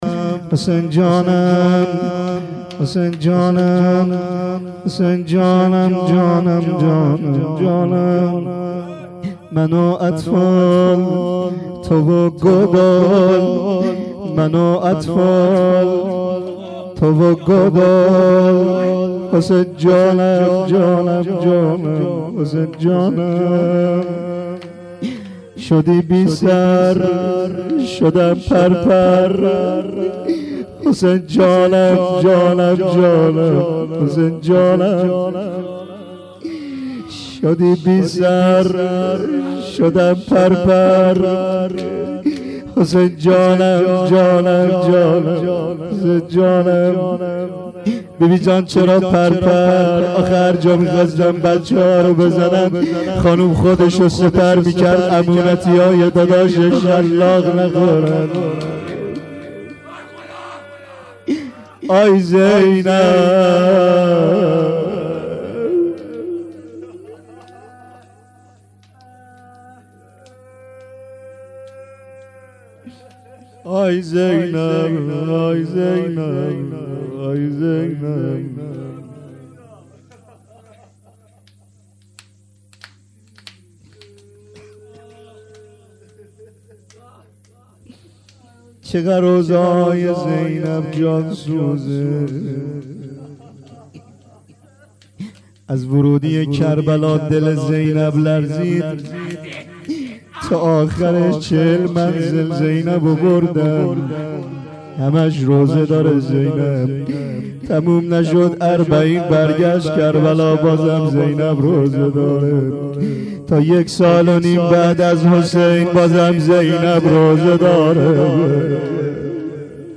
روضه آخر.MP3